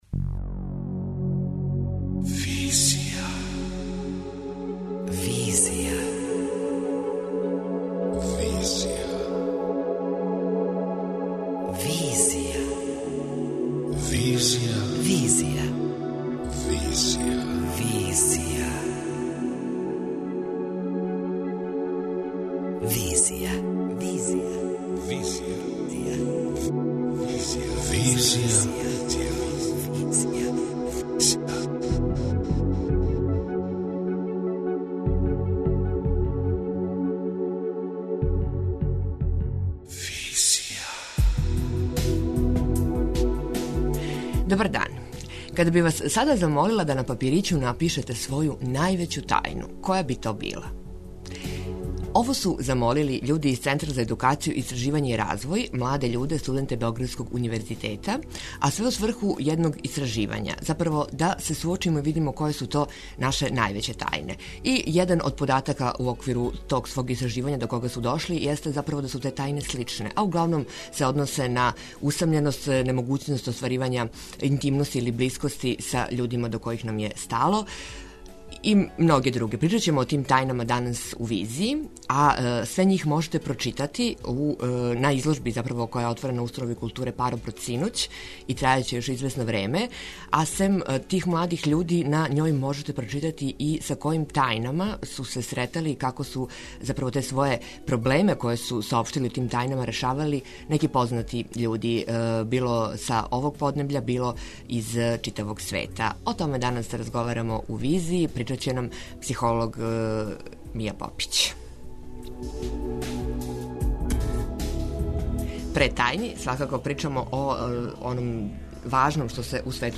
преузми : 27.56 MB Визија Autor: Београд 202 Социо-културолошки магазин, који прати савремене друштвене феномене.